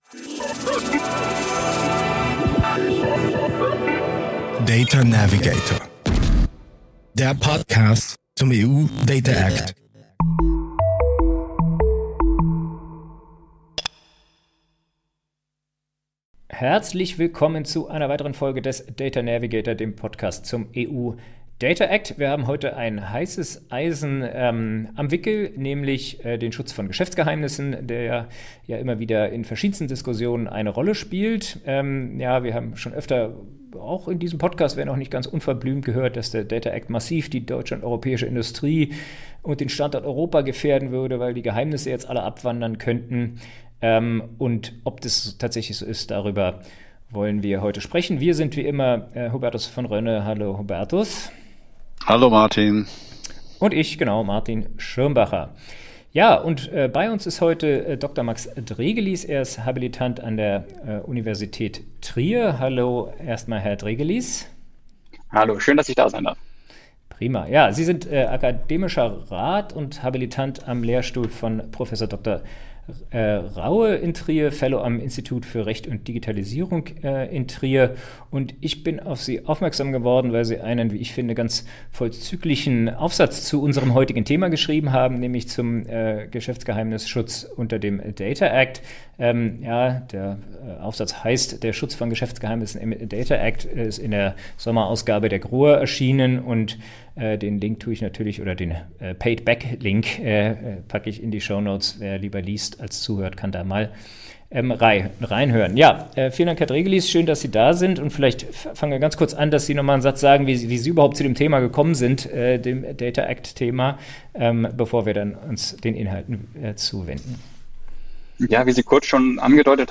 Das war der Anlass für unser Gespräch in dieser Folge des Data Navigator.